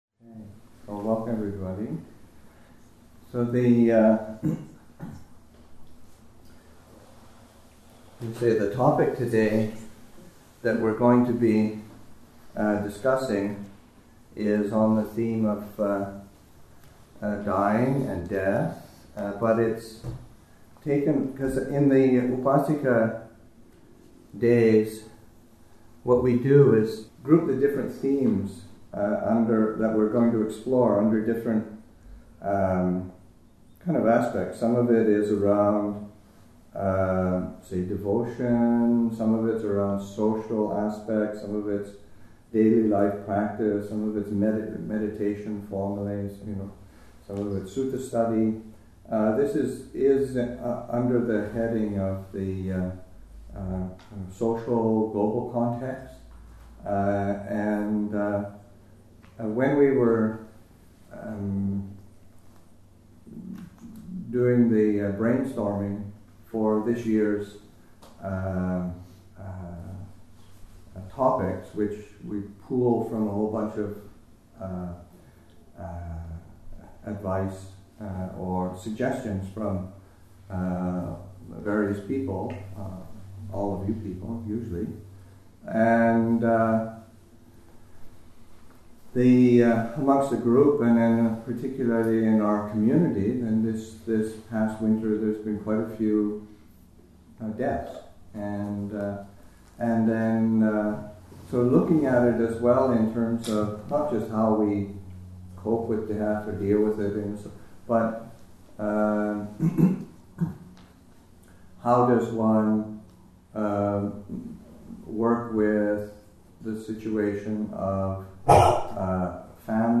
Abhayagiri Buddhist Monastery in Redwood Valley, California